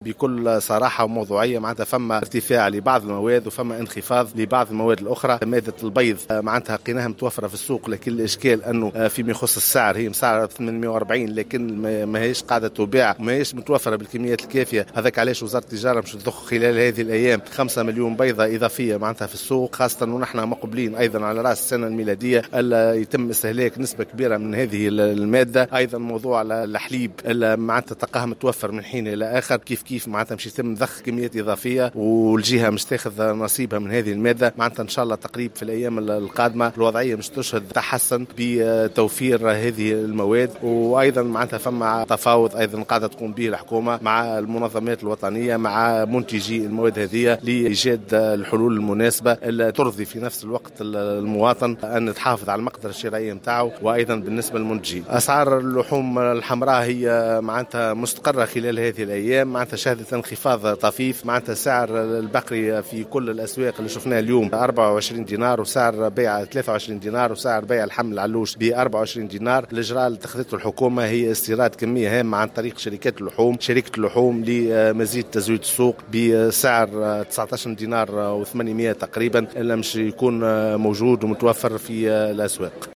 وأضاف أيضا في تصريح اليوم لـ"الجوهرة أف أم" على هامش اجتماع اللجنة الجهوية بسوسة لمتابعة تطور الأسعار والتصدي للتهريب أنه سيتم ضخ كميات تقدر بـ 5 ملايين بيضة لدعم المخزون التعديلي المقدر حاليا ب 30 مليون بيضة ، إضافة إلى ضخ كميات إضافية من مادة الحليب.